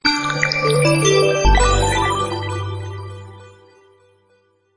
Index of /phonetones/unzipped/LG/KH1200/Event sounds
Power On.aac